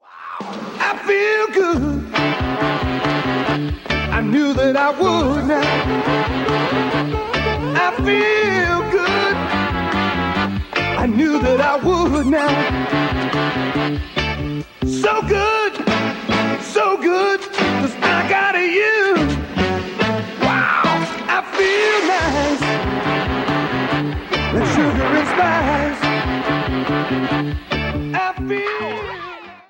Short music sample of a cover song